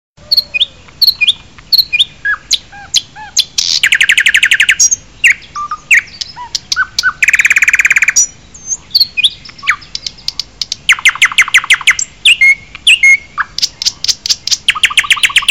Genre: Nada dering binatang Tag
Suara burung nge-tweet bikin adem, kayak lagi chill di alam.